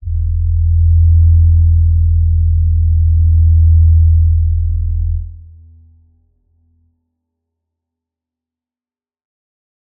G_Crystal-E2-f.wav